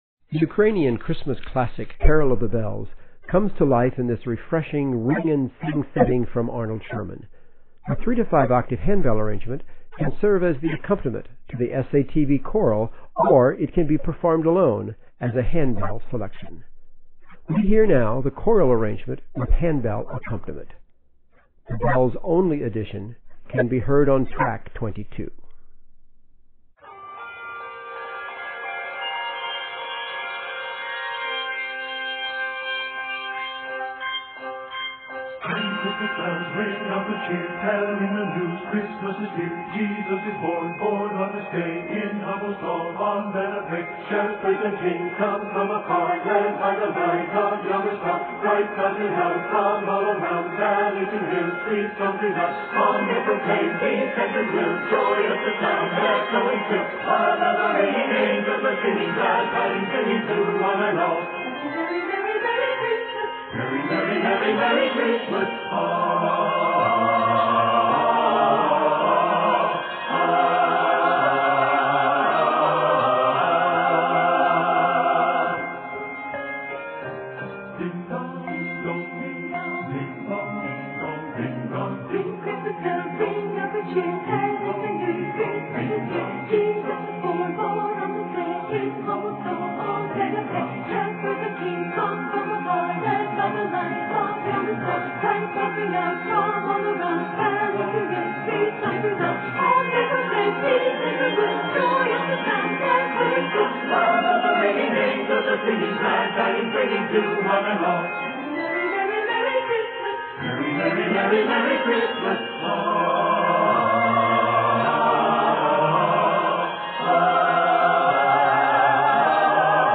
The classic Ukrainian carol